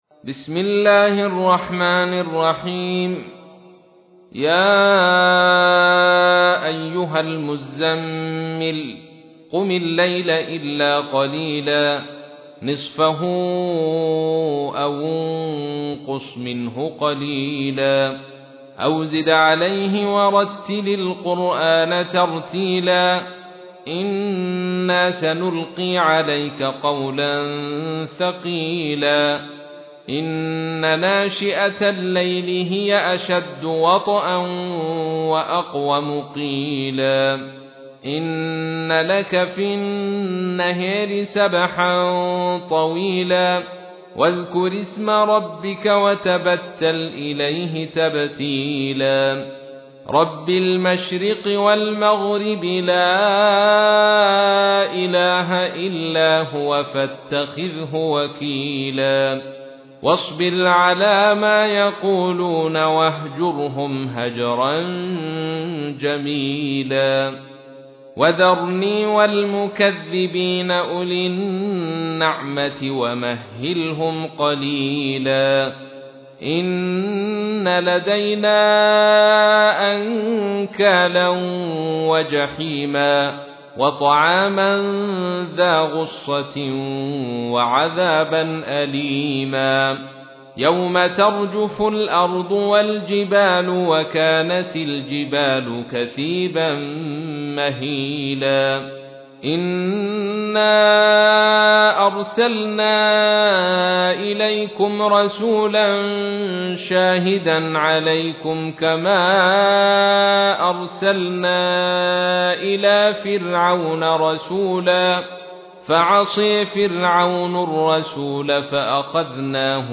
تحميل : 73. سورة المزمل / القارئ عبد الرشيد صوفي / القرآن الكريم / موقع يا حسين